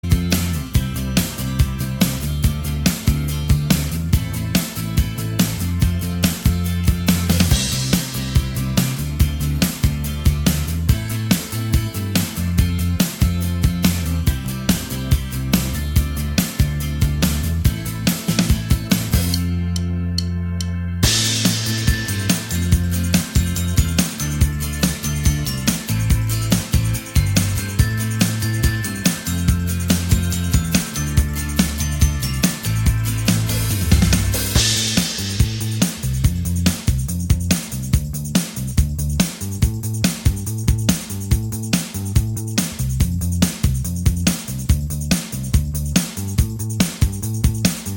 Minus Main Guitar Pop (2010s) 3:54 Buy £1.50